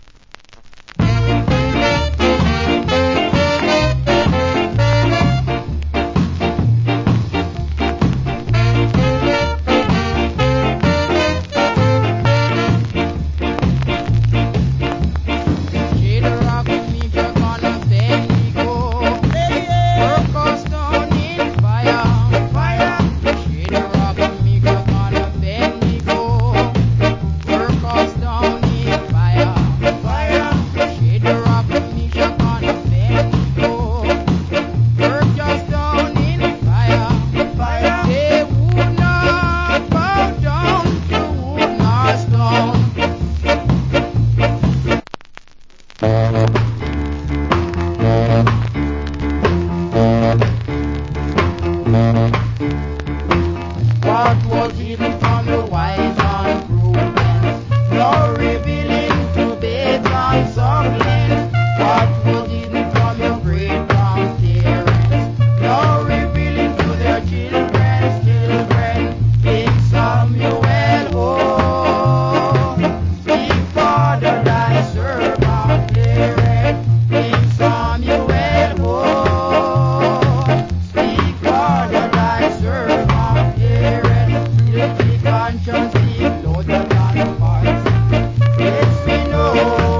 Wicked Ska Vocal.